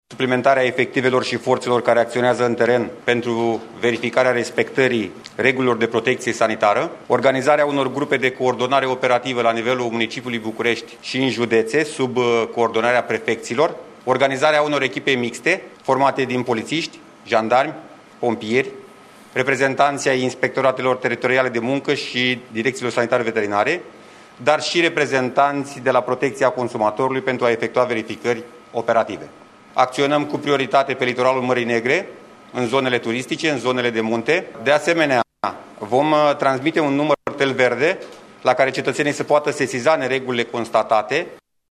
Ministrul Sănătății, Nelu Tătaru, și secretarul de stat, Bogdan Despescu, au susținut declarații de presă în urmă cu puțin timp, după întâlnirea premierului Ludovic Orban cu miniștri și șefi de instituții cu atribuții de control în contextul crizei COVID-19.